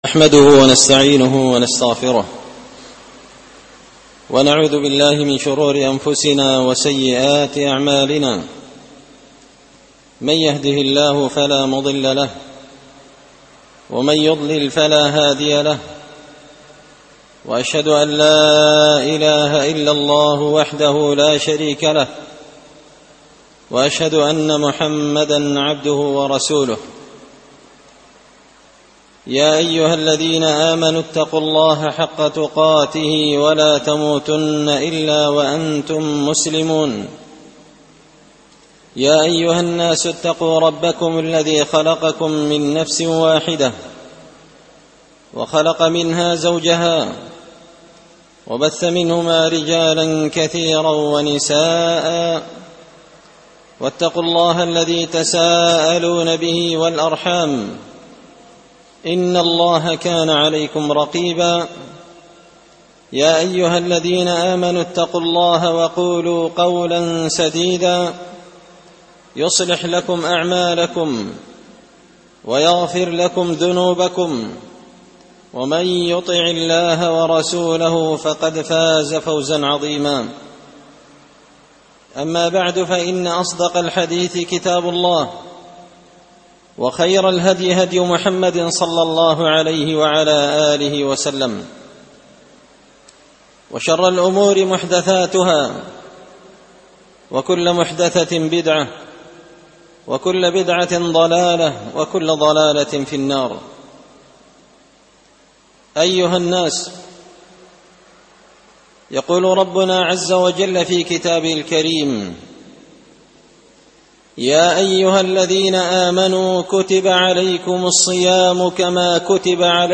خطبة جمعة بعنوان -الصيام آداب وأحكام
دار الحديث بمسجد الفرقان ـ قشن ـ المهرة ـ اليمن